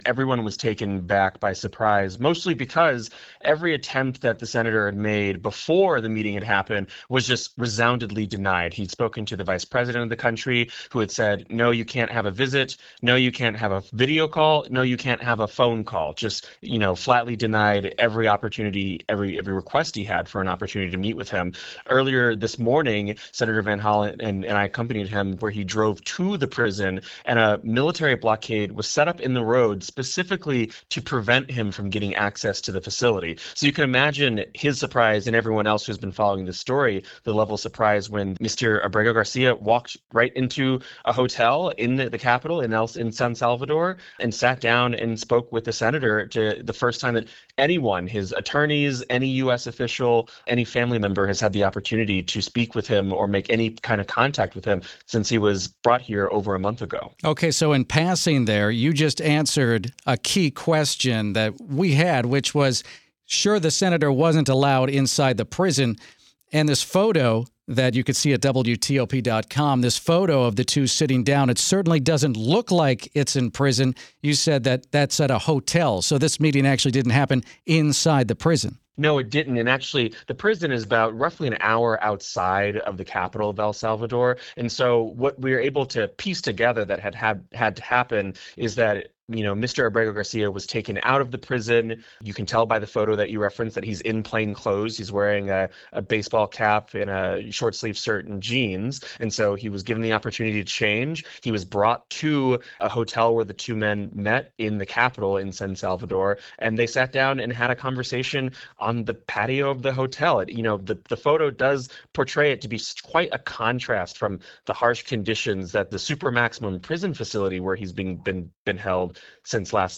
joined WTOP from San Salvador with the latest on Van Hollen's meeting